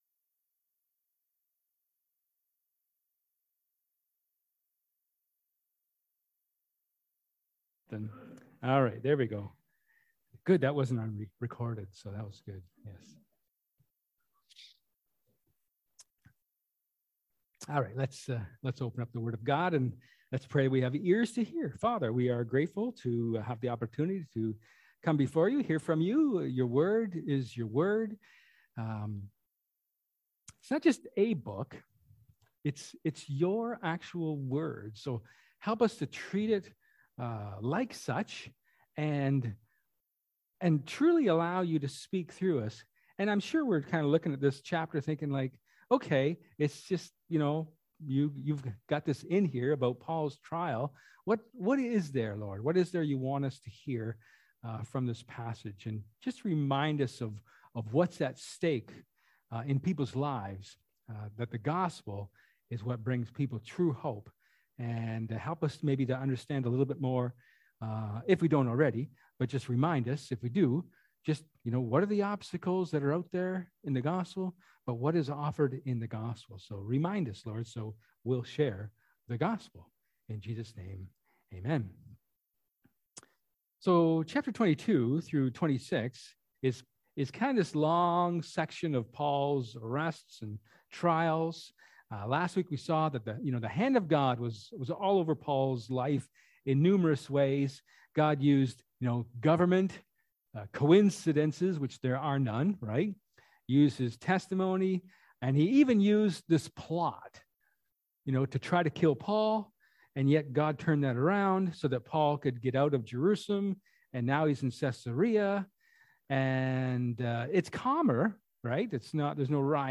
Ecclesiastes 9 Service Type: Sermon